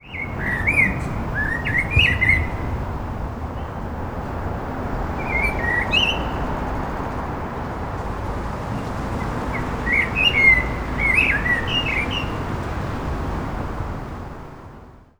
In the middle stage, we recorded additional point sound sources and used the shotgun microphone Sennheiser MKH416 to record more precise point sound sources, such as bells, chirps, kids and so on.
Chirp.wav